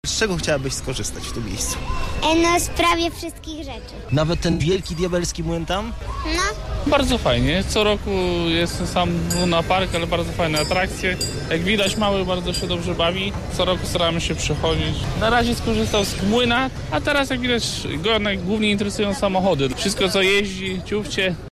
Wesołe miasteczko – to kolejna winobraniowa atrakcja, która wystartowała przed południem.